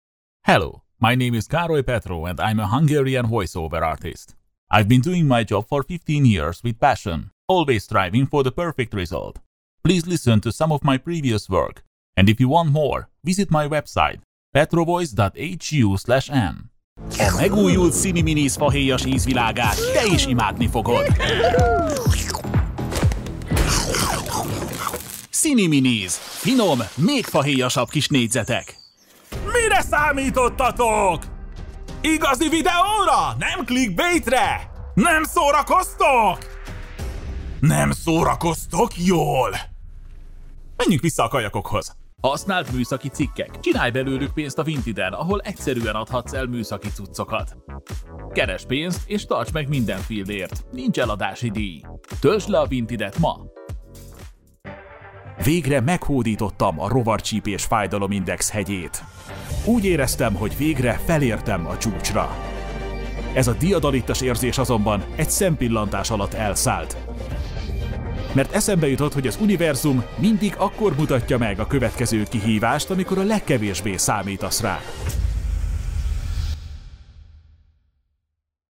VOICE ACTOR DEMOS
Intro, Dubbing, Commercial, Explainer, Online, Vo
Words that describe my voice are friendly, informative, warm.